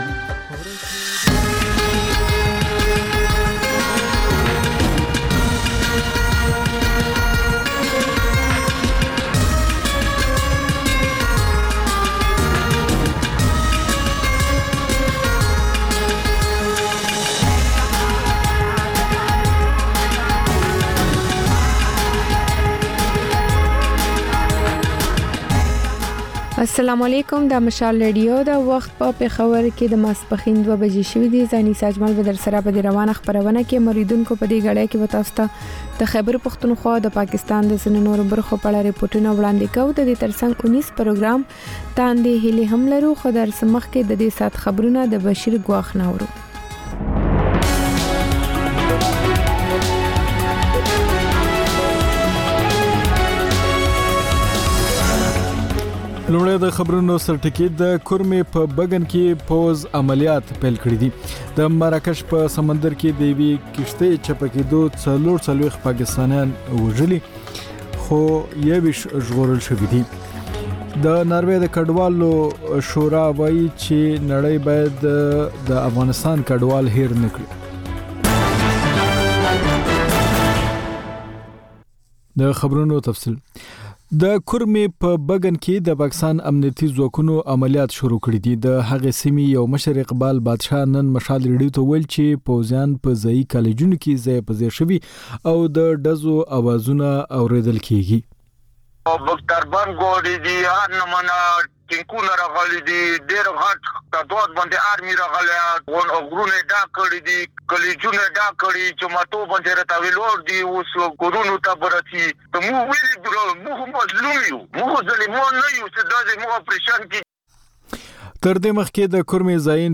په دې خپرونه کې لومړی خبرونه او بیا ځانګړې خپرونې خپرېږي.